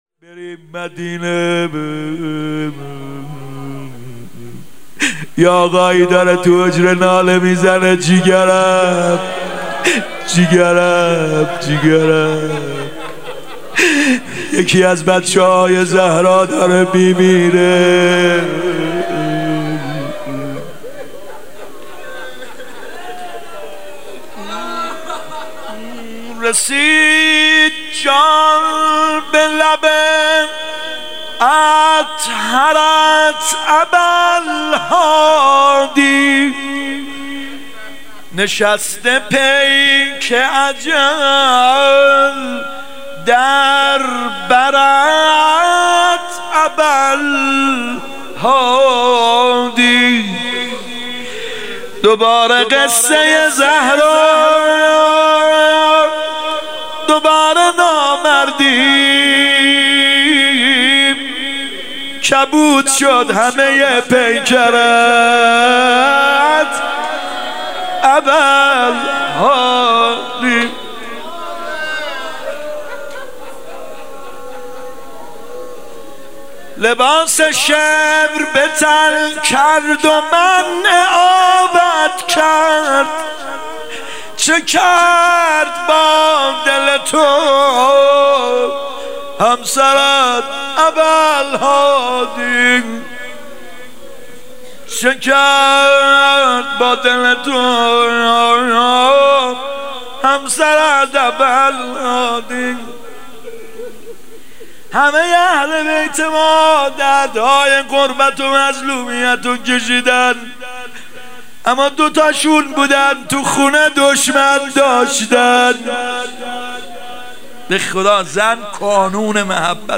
شهادت امام جواد 96 (هیات یامهدی عج)